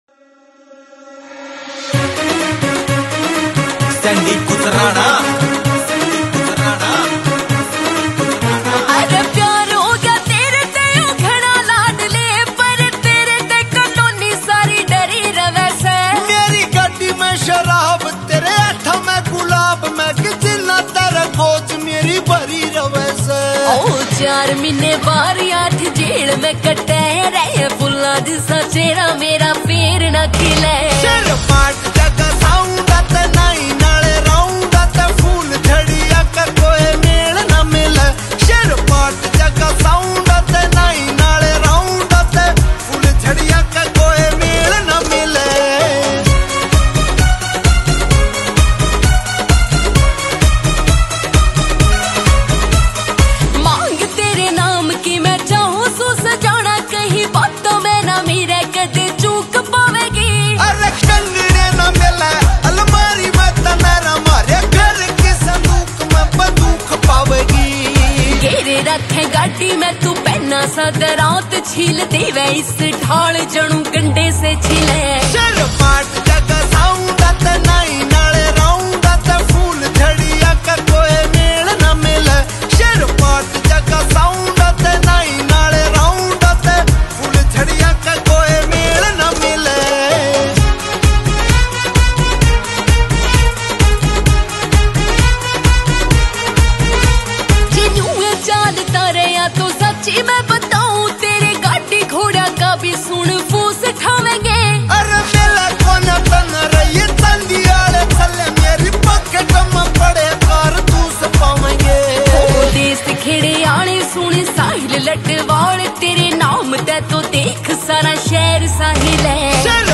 Releted Files Of New Haryanvi Song 2025